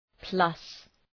Προφορά
{plʌs}